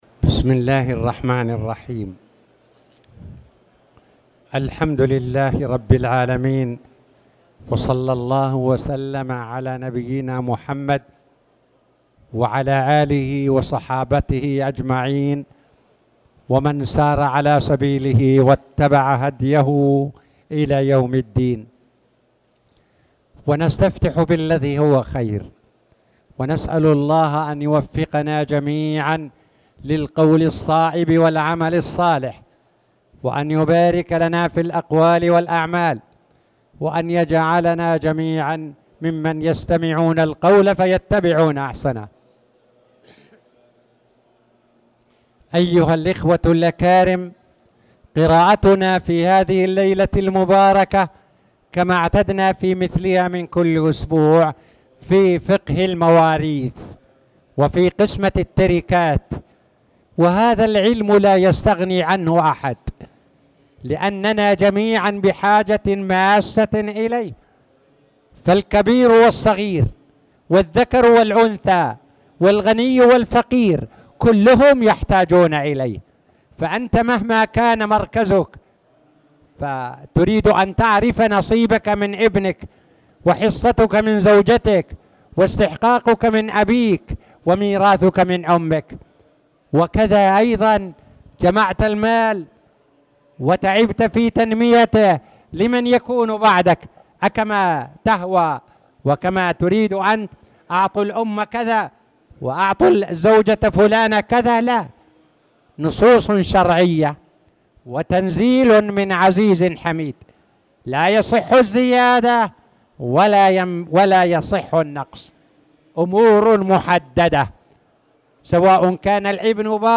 تاريخ النشر ١٦ محرم ١٤٣٨ هـ المكان: المسجد الحرام الشيخ